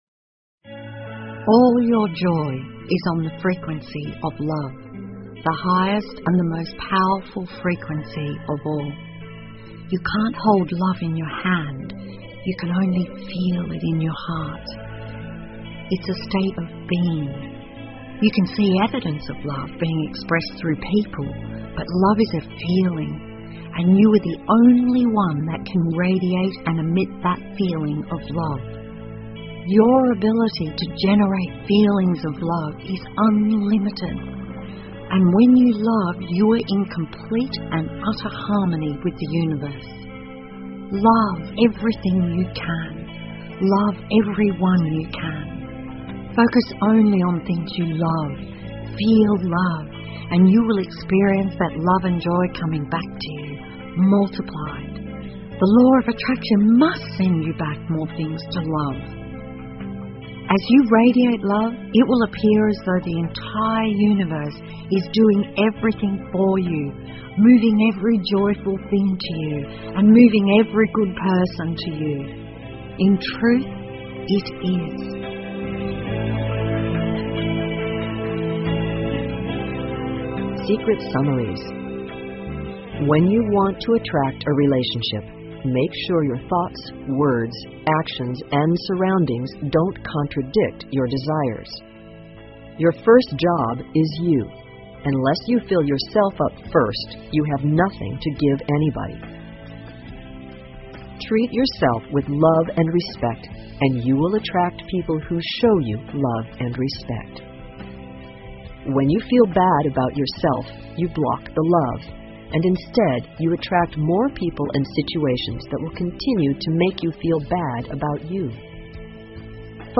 英文有声畅销书-秘密 3-14 Secret Summaries 听力文件下载—在线英语听力室